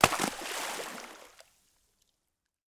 SPLASH_Small_02_mono.wav